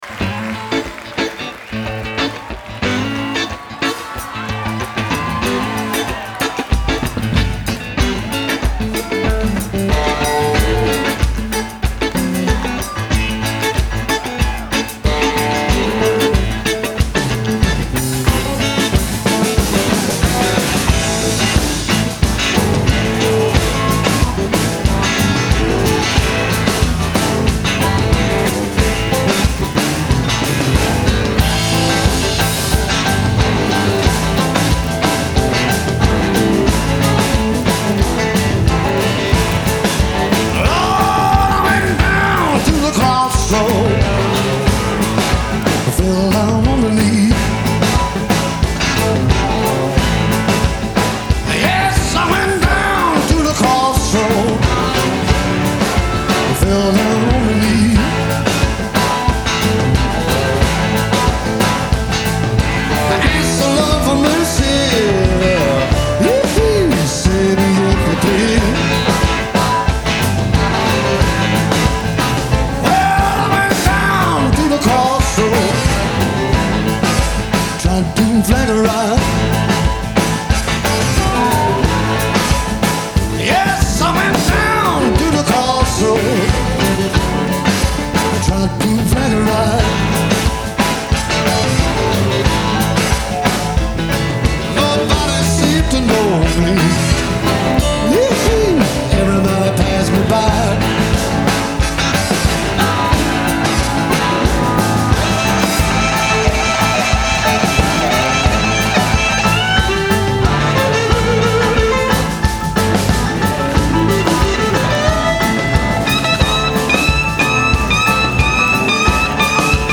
Genre : Blues ,Rock
Live at the Fillmore, San Francisco, 1994